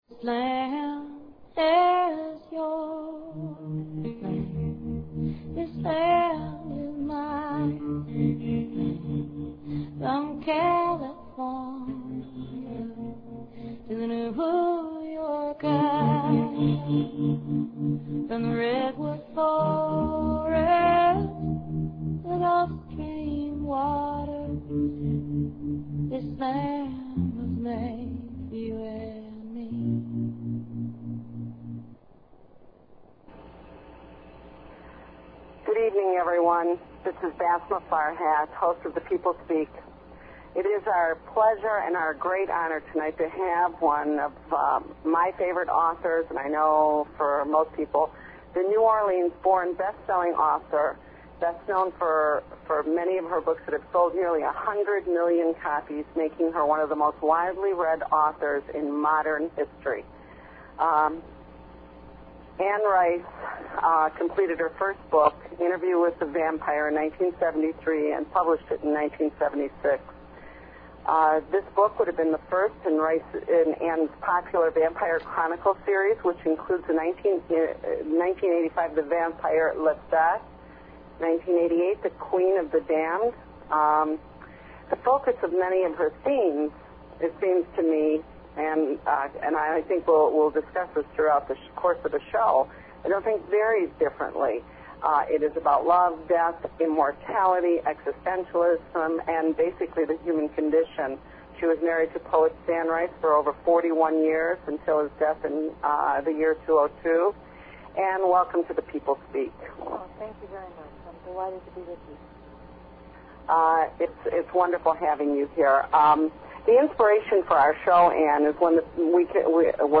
The People Speak with Guest, ANNE RICE - author Interview with the Vampire